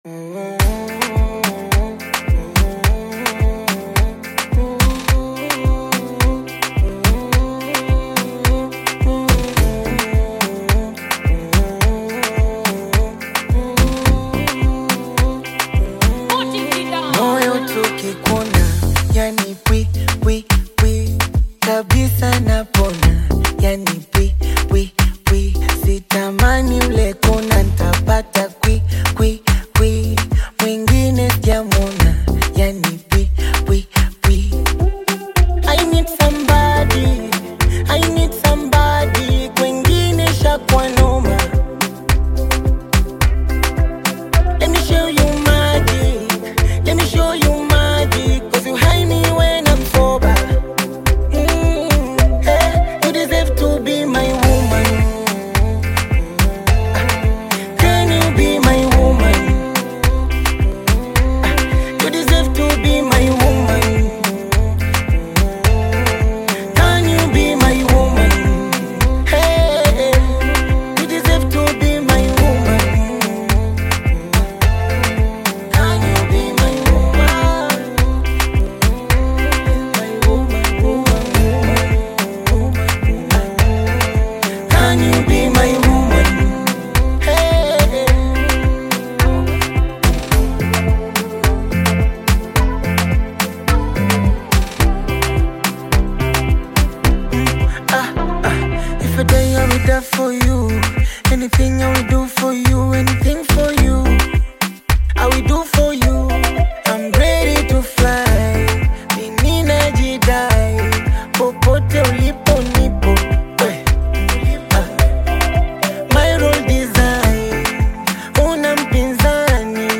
Tanzanian Bongo Flava
African Music